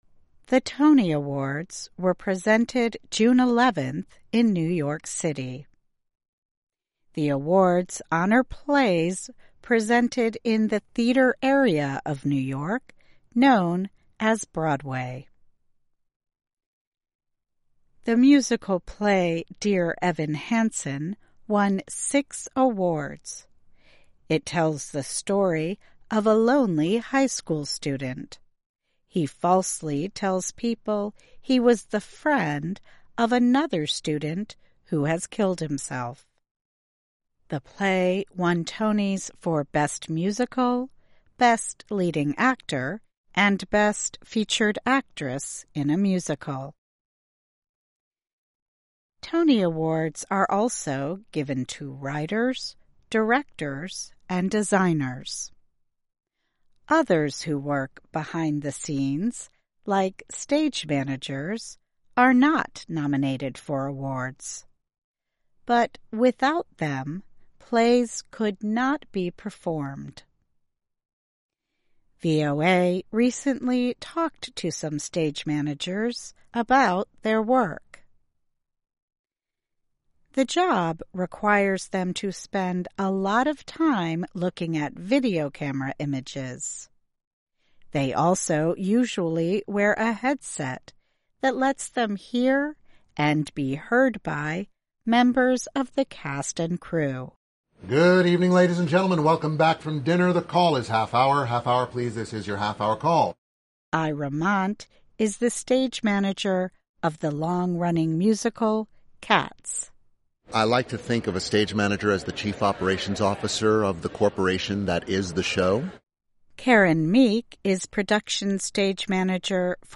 慢速英语:Stage Managers Make Broadway Shine